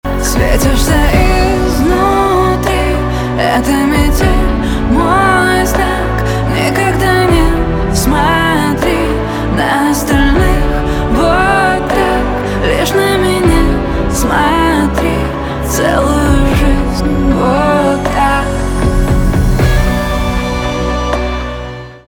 Гитара , поп
чувственные , битовые